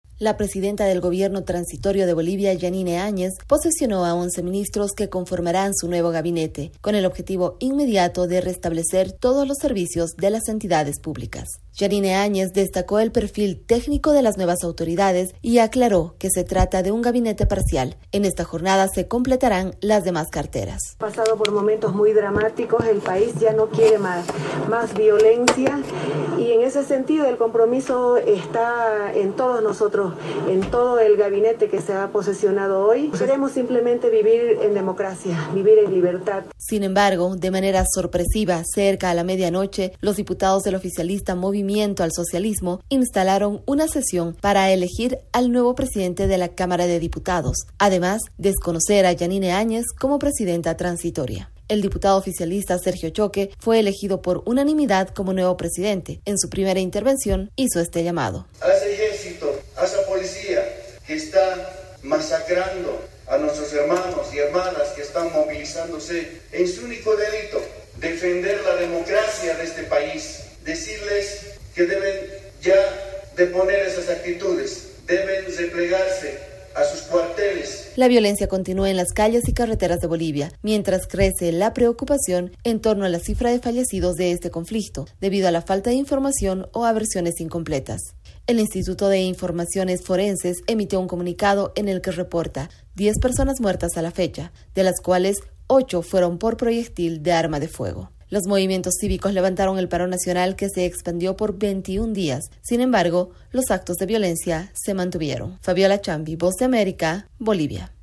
VOA: Informe de Bolivia